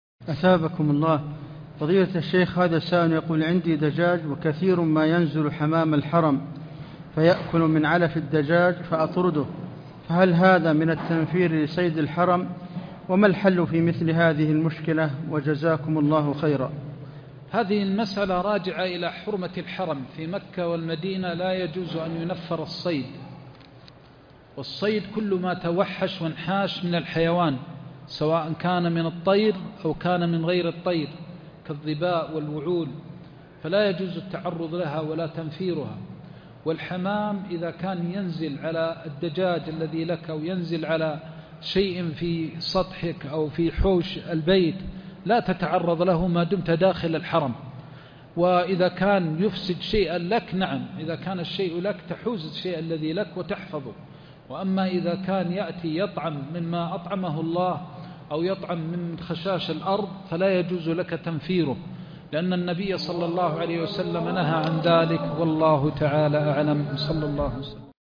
عنوان المادة درس عمدة الأحكام 1444_1_20 - السؤال الثالث